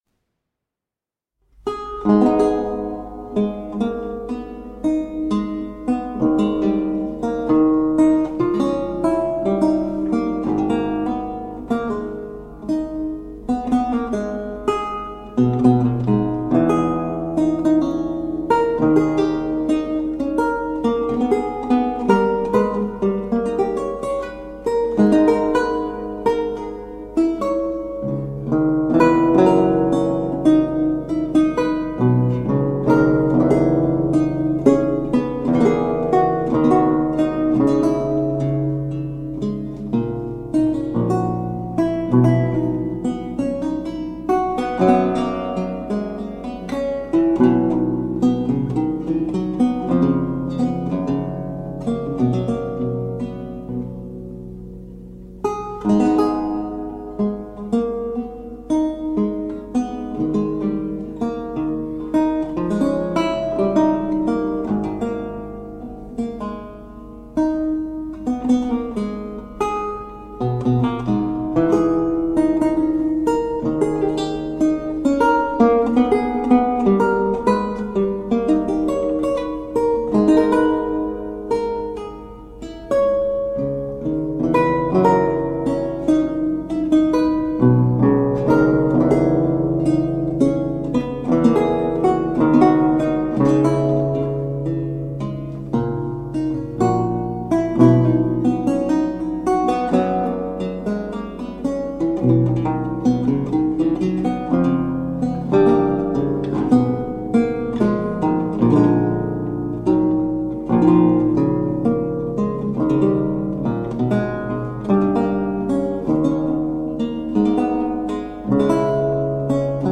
A marvelous classical spiral of lute sounds.
Classical, Baroque, Instrumental
Lute